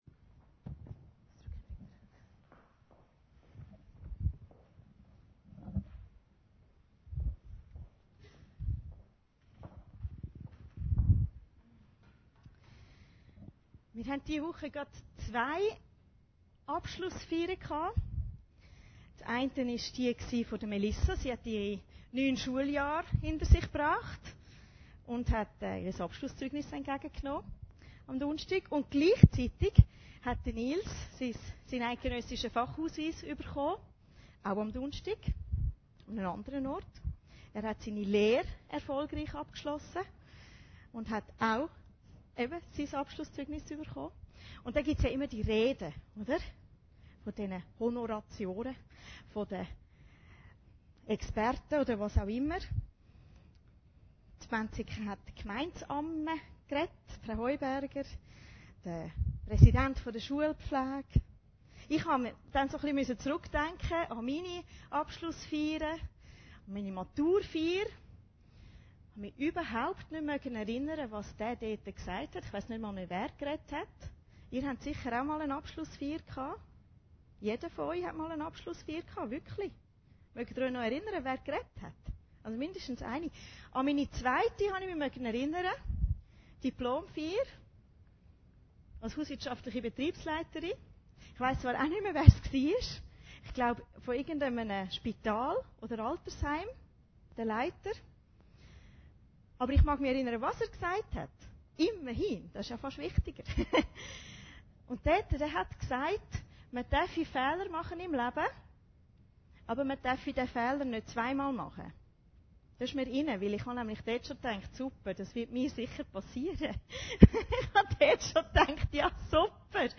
Predigten Heilsarmee Aargau Süd – Das vierfache Ackerfeld